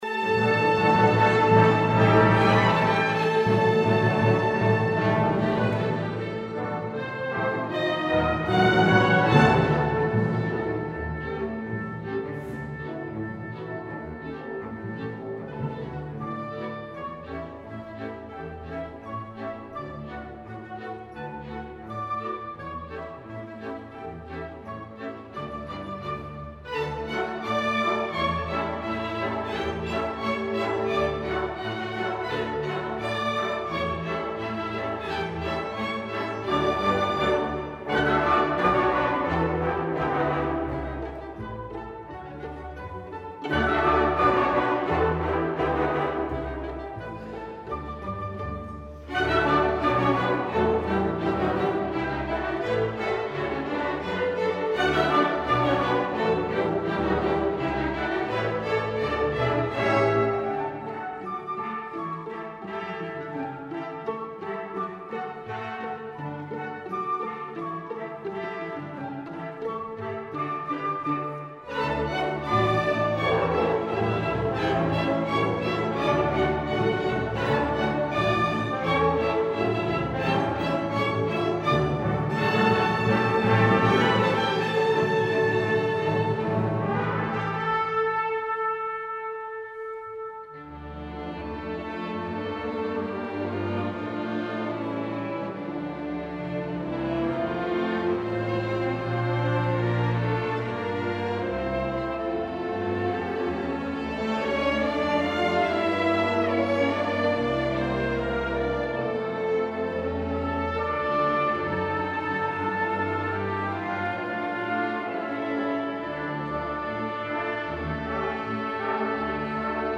2016-05-28 Konsert Kumla kyrka tillsammans med Kumla Hallsbergs orkesterförening